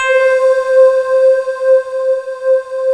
55A-SYN01-C3.wav